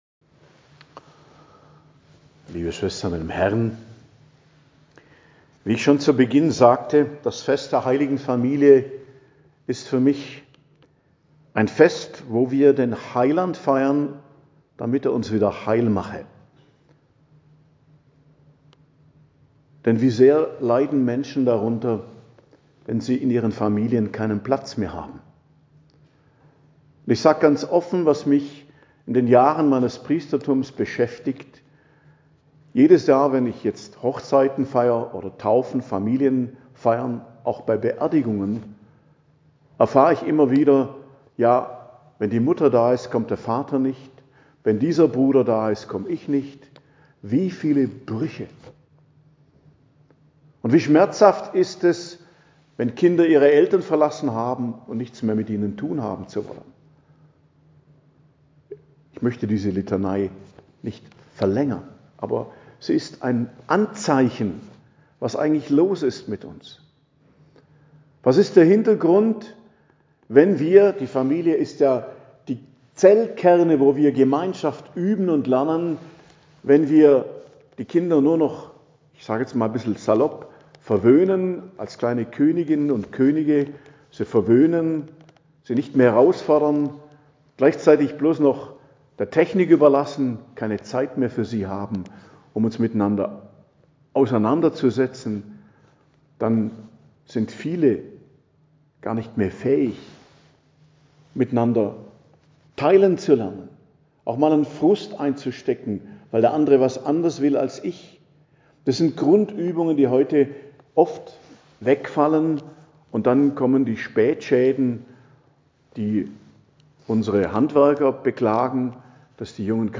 Predigt am Fest der Heiligen Familie, 28.12.2025